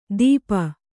♪ dīpa